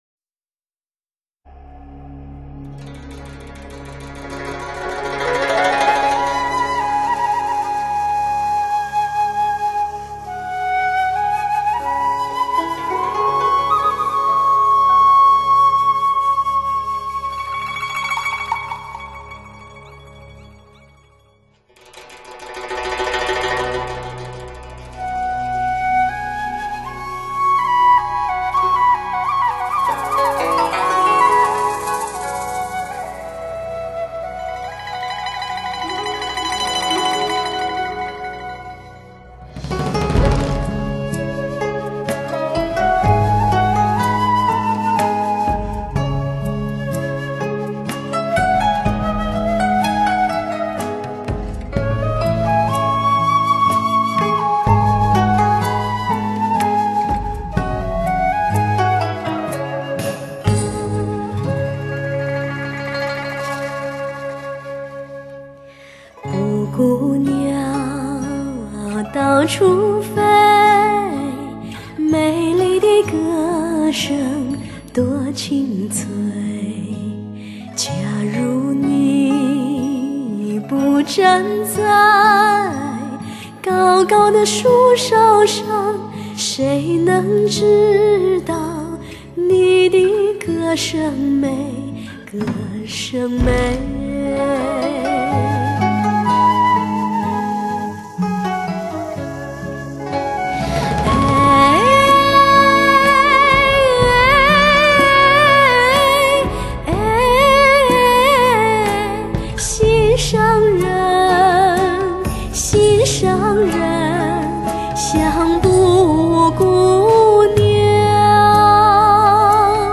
是充满了浓郁民族风情的蒙古民歌。
大自然的慈爱与恩典，蒙古民歌的悠扬与舒展，